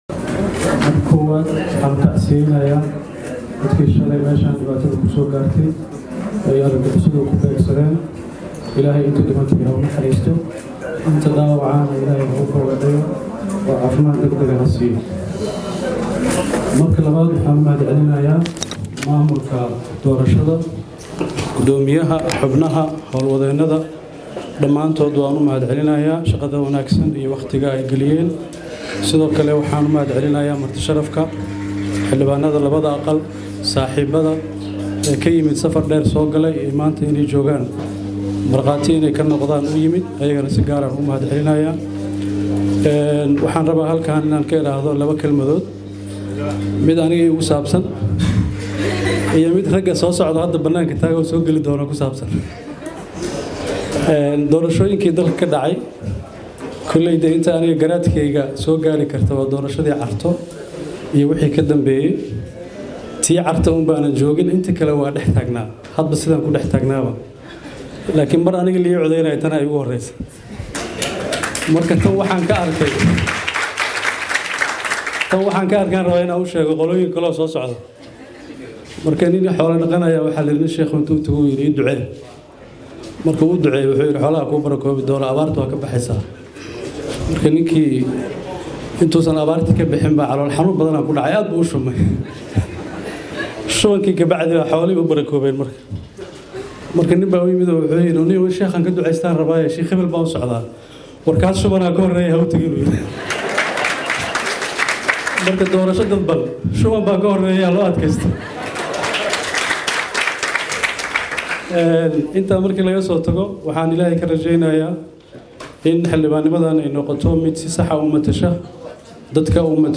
Fahad Yaasiin oo maanta lagu doortay magaalada Beledweyne ayaa goobtii lagu doortay kula hadlay ergadii iyo dadkii kale ee goobjooga ka ahaa doorashada.
Fahad oo hadallo kaftan ah goobta ka jeediyay ayaa u muuqday in uu muujinayo in doorashada dadban ay noqon doonto mid natiijooyin aanan dad badan farax galin. Waa mararka dhifta ah ee la arkay Fahad oo fagaare ka hadlaya, wuxuuna markan ballanqaaday in kursigiisa uu ugu adeegi doono Hirshabeelle iyo Soomaaliyaba.